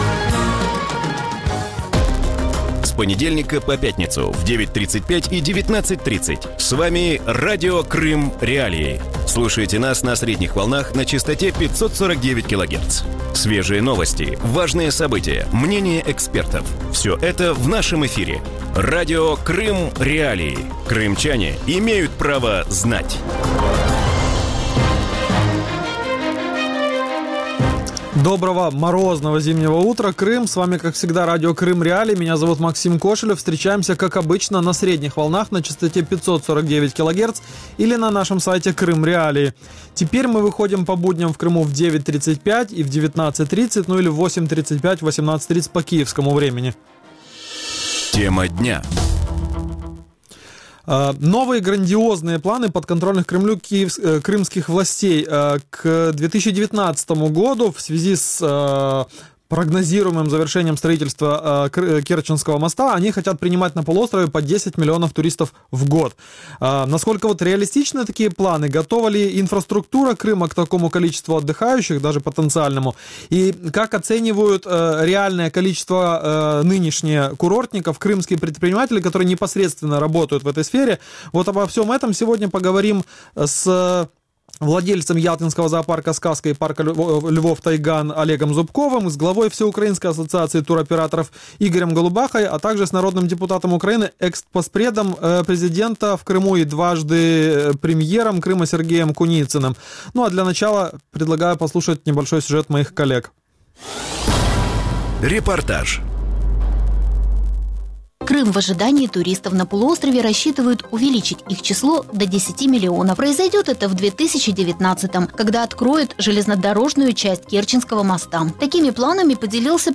Вранці в ефірі Радіо Крим.Реалії говорять про грандіозні плани підконтрольної Росії влади Криму прийняти до 2019 року 10 мільйонів туристів на півострові. Наскільки реалістичні такі плани, чи готова інфраструктура Криму до такої кількості приїжджих на відпочинок і що думають про це кримські підприємці?